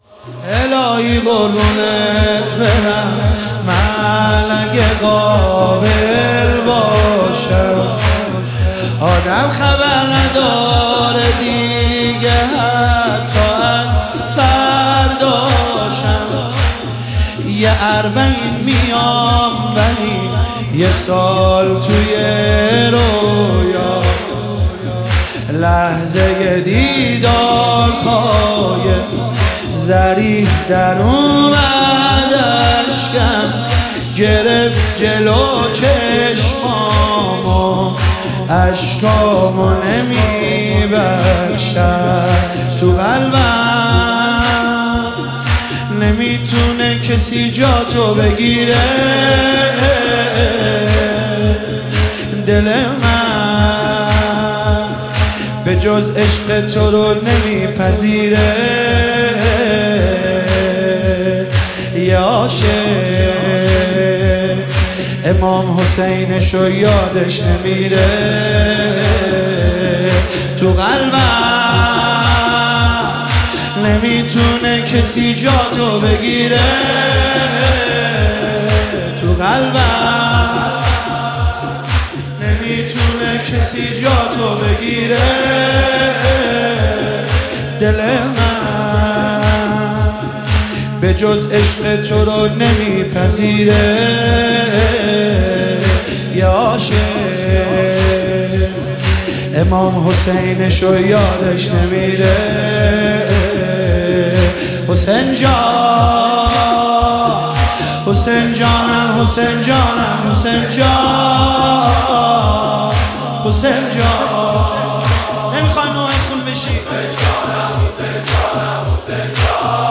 مداحی_شهادت حضرت زهرا